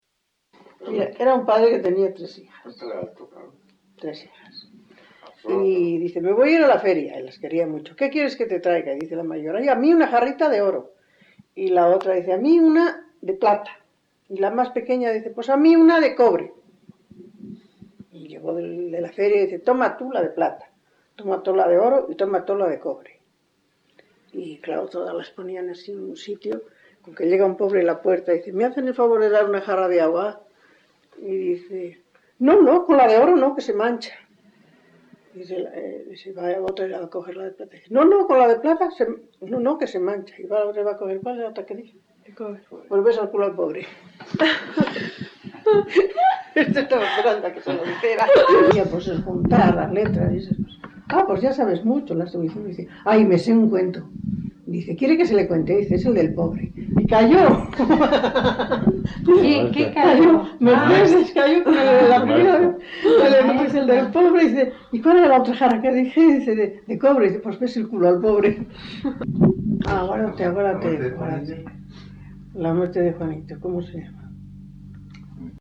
Grabación realizada en La Overuela (Valladolid), en 1977.
Género / forma: Canciones populares-Valladolid (Provincia) Icono con lupa